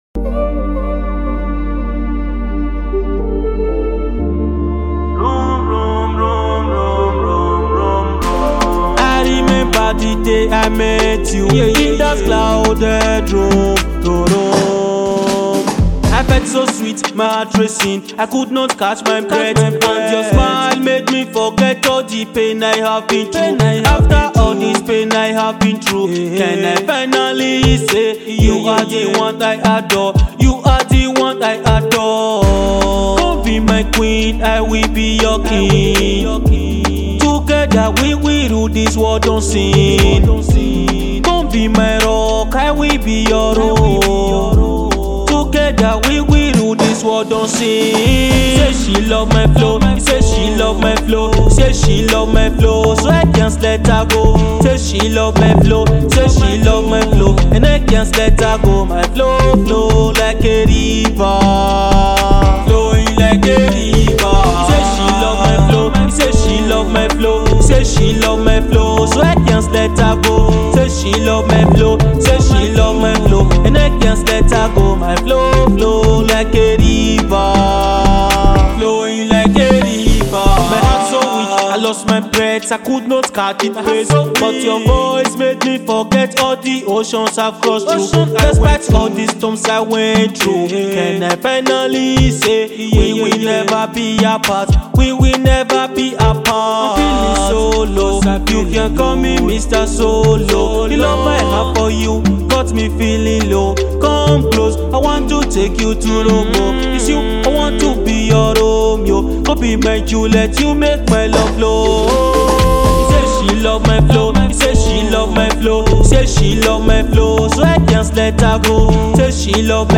Nigerian Top Notch singer-songwriter
With a captivating melody and enchanting lyrics